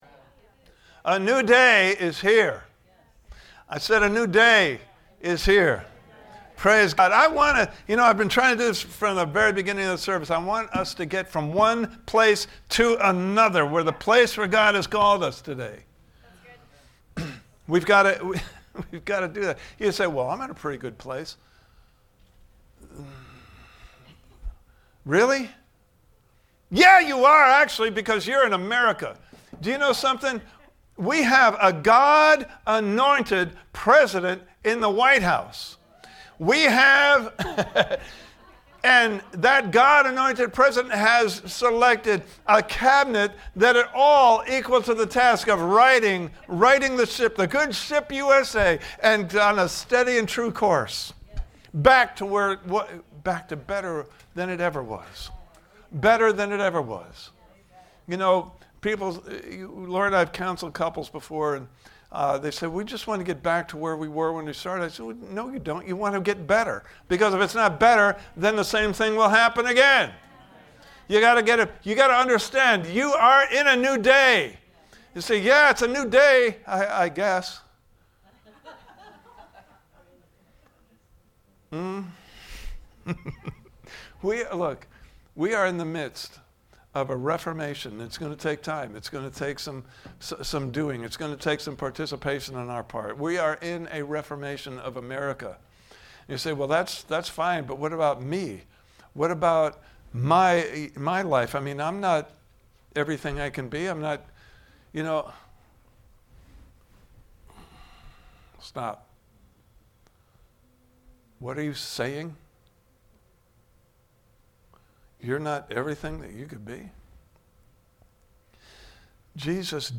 Series: Living a Redeemed Life Service Type: Sunday Morning Service « Part 3